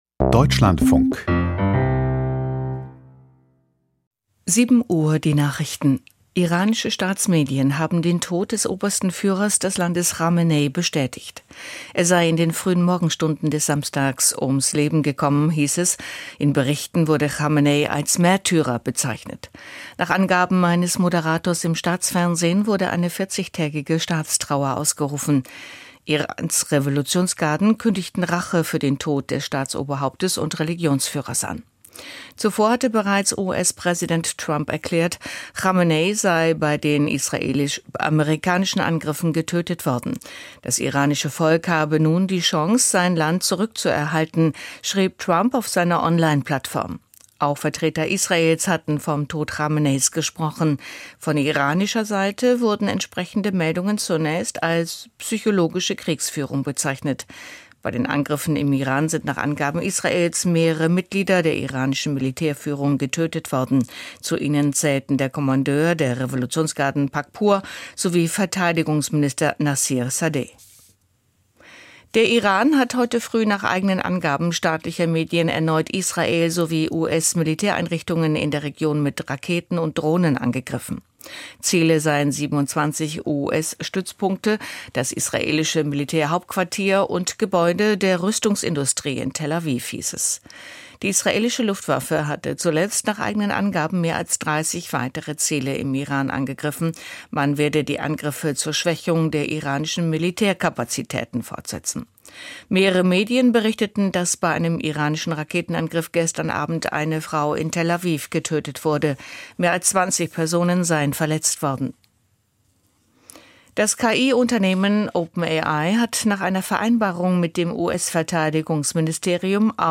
Die Nachrichten vom 01.03.2026, 07:00 Uhr
Aus der Deutschlandfunk-Nachrichtenredaktion.